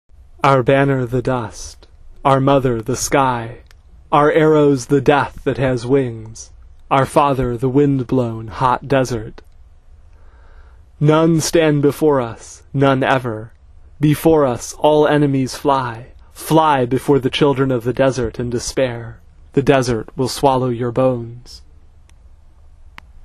They really should be sung, but I... uh... have a problem with carrying tunes, so for now you will have to settle for the songs read aloud as poems.